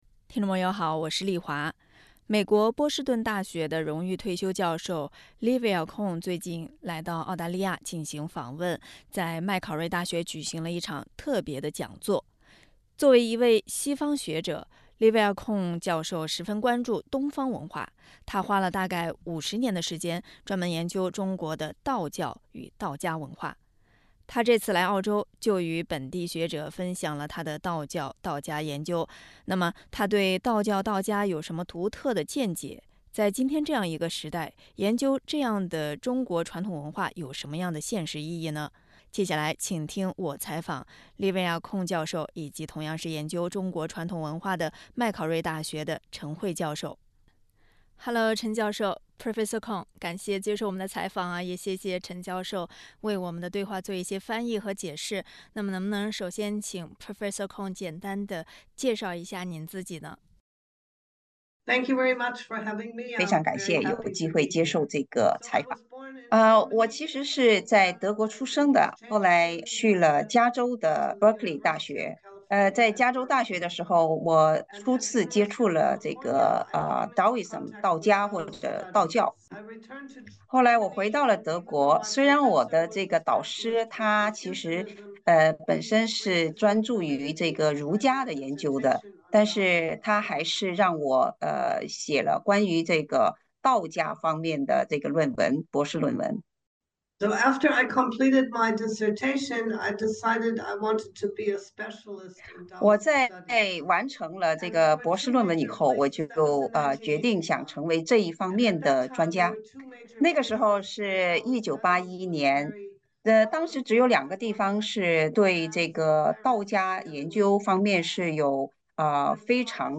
道家文化对我们当今社会有什么意义？点击音频收听采访。